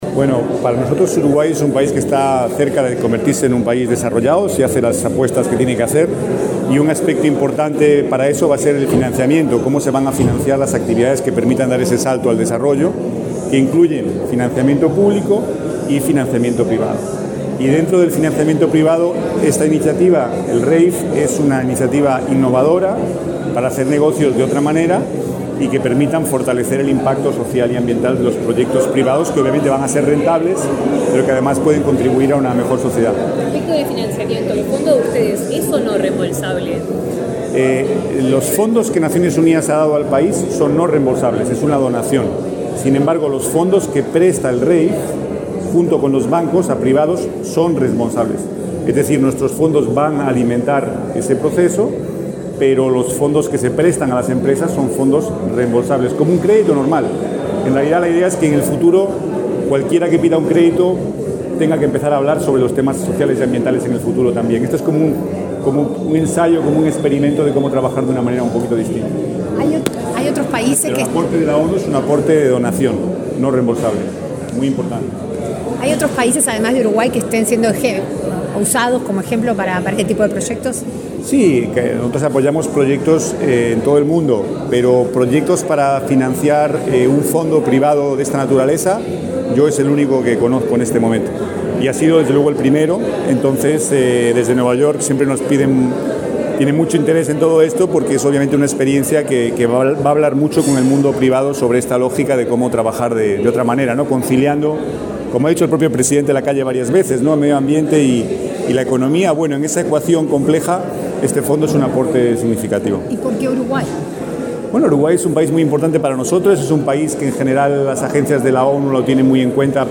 Declaraciones del Coordinador Residente de las Naciones Unidas, Pablo Ruiz Hiebra
Declaraciones del Coordinador Residente de las Naciones Unidas, Pablo Ruiz Hiebra 24/10/2023 Compartir Facebook X Copiar enlace WhatsApp LinkedIn El Coordinador Residente de las Naciones Unidas, Pablo Ruiz Hiebra, dialogó con la prensa, luego de participar del acto de presentación de los primeros proyectos aprobados en el marco del Fondo de Innovación en Energías Renovables (REIF, por sus siglas en inglés).